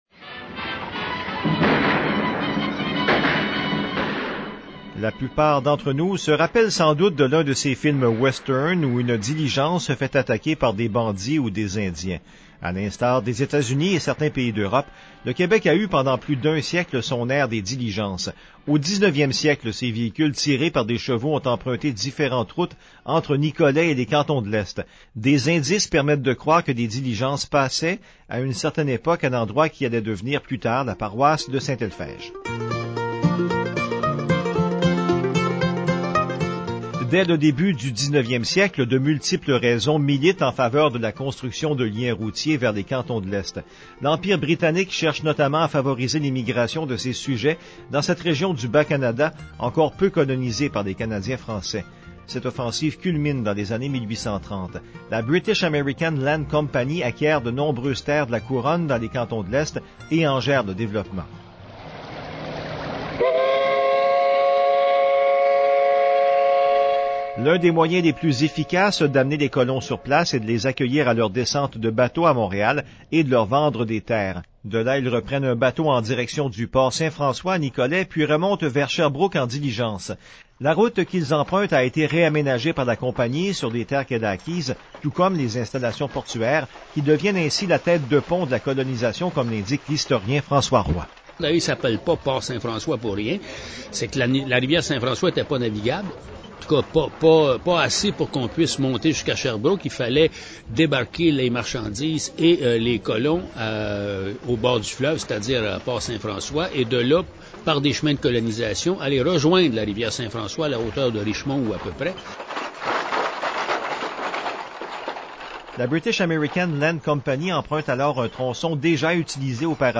Un reportage
Cette capsule historique fait partie d’un projet initié par la MRC de Nicolet-Yamaska dans le cadre de son Entente de développement culturel avec le ministère de la Culture et des Communications et réalisé de concert avec VIA 90.5 FM.